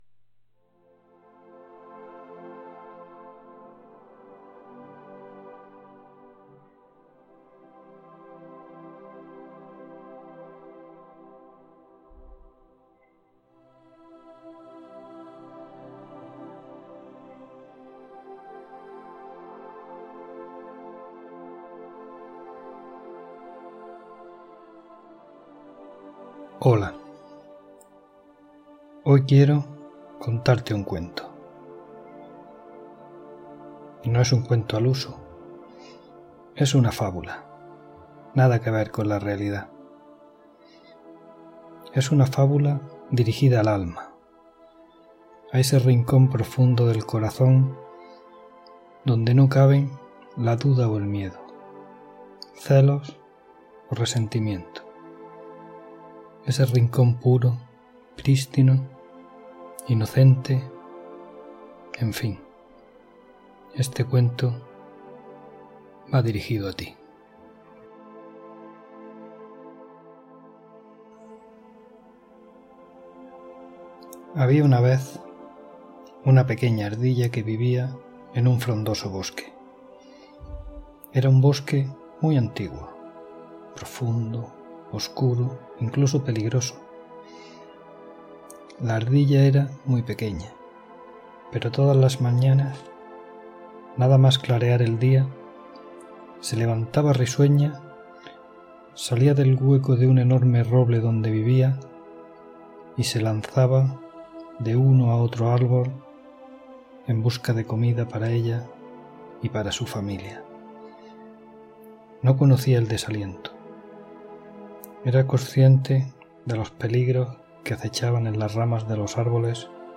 Cuento de la Ardilla An.
Este es un cuento que escribí y narré para una gran amiga que estaba pasando por un mal momento.